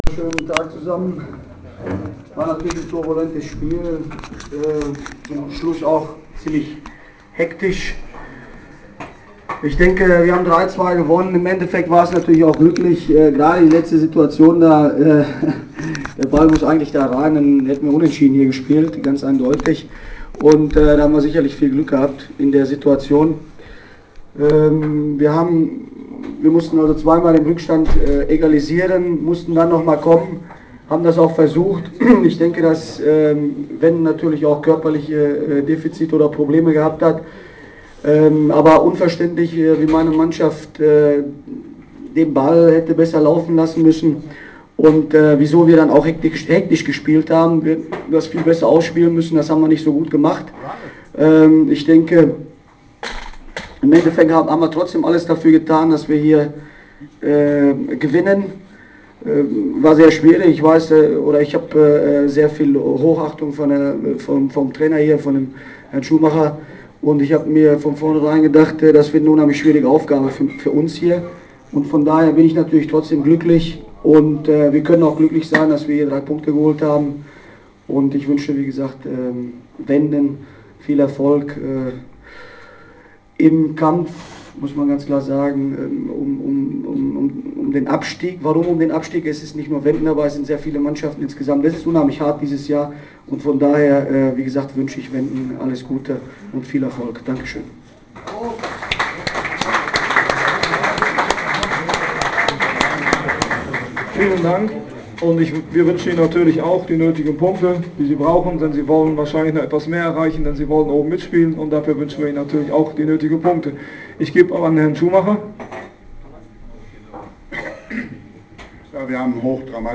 Pressekonferenz zum Download